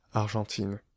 Ääntäminen
Ääntäminen Tuntematon aksentti: IPA: /aʁ.ʒɑ̃.tin/ Haettu sana löytyi näillä lähdekielillä: ranska Käännös Substantiivit 1. аржентинка {f} Muut/tuntemattomat 2.